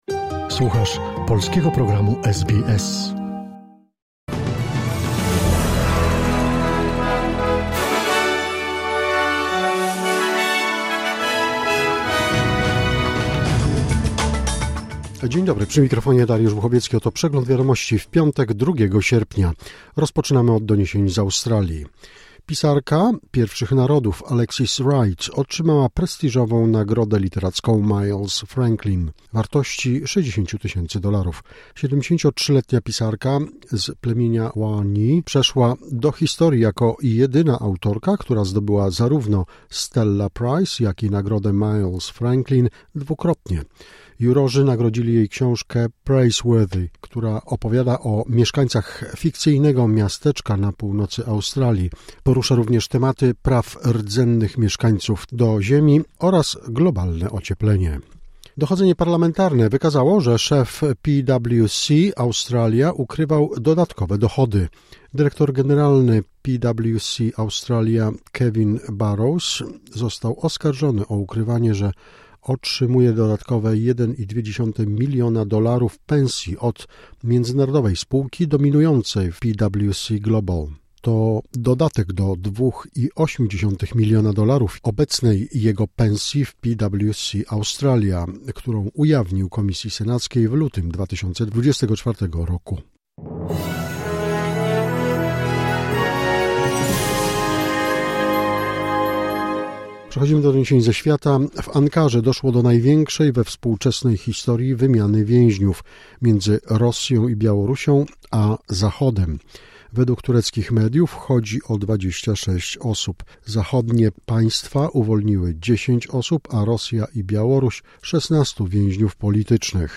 Wiadomości 2 sierpnia SBS News Flash